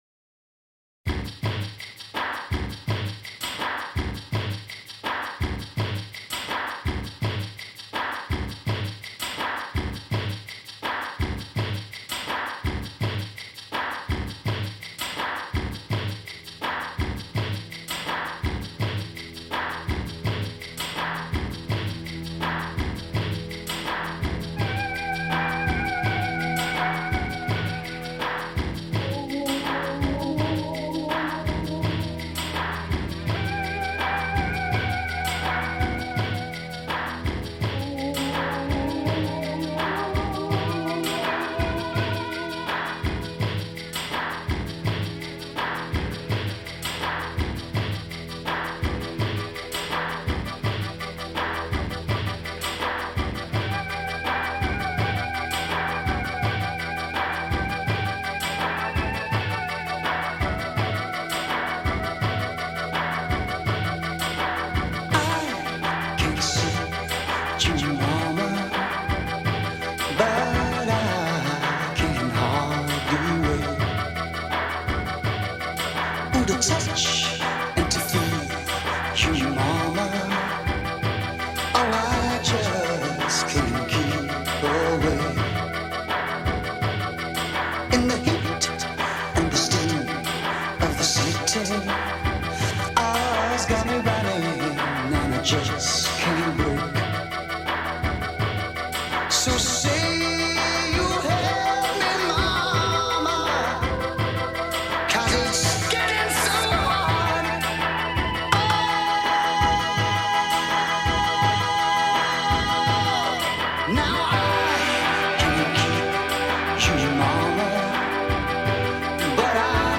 Progressive Rock, Pop Rock